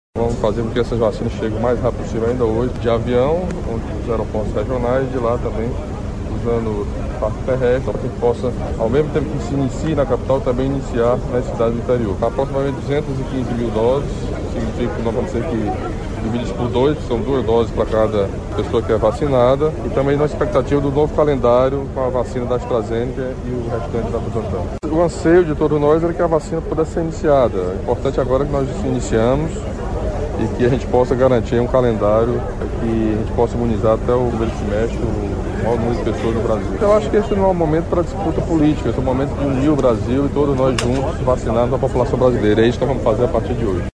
O governador Camilo Santana falou sobre o início da vacinação hoje e destacou o momento, como sendo um grande anseio da população brasileira.